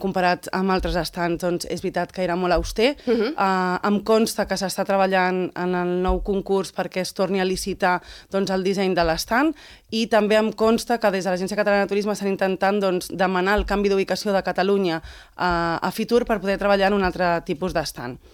Ho ha explicat a l’espai ENTREVISTA POLÍTICA de Ràdio Calella TV, on s’ha alineat amb el posicionament de Buch respecte a la necessitat de lleis més dures per combatre la multireincidència.